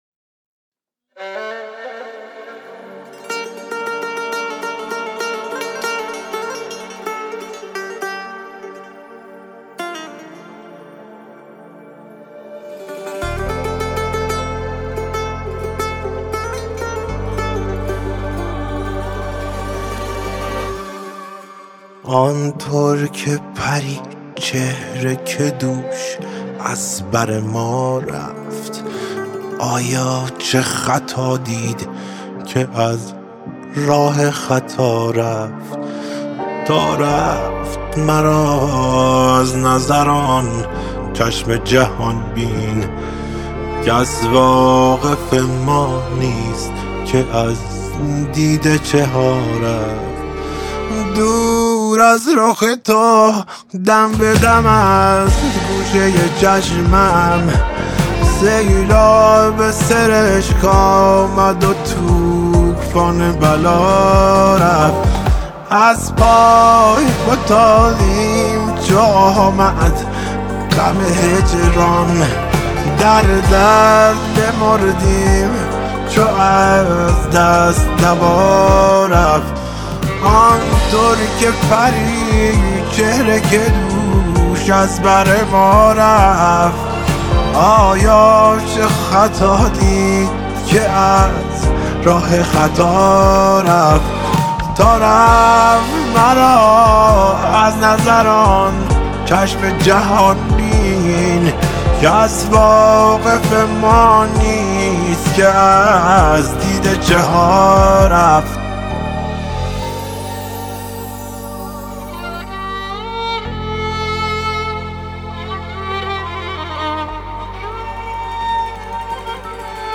موسیقی پاپ
سه تار
گیتار کلاسیک
کمانچه